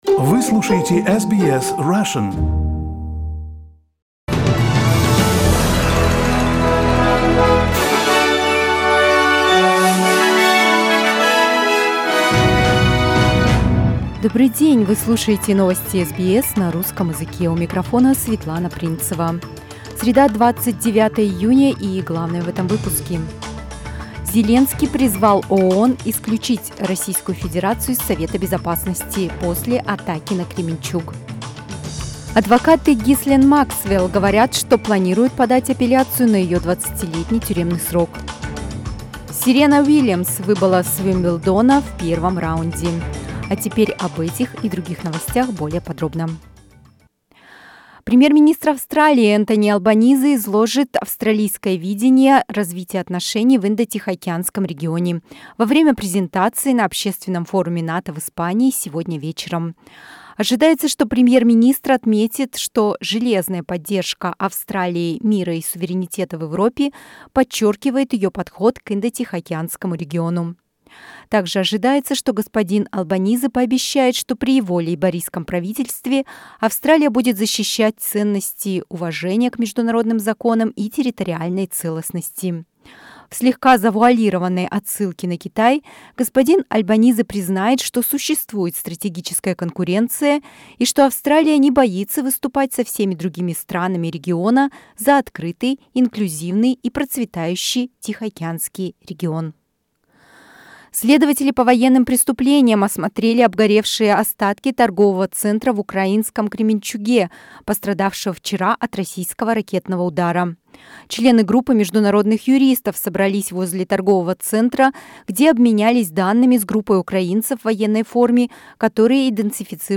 SBS News in Russian - 29.06.22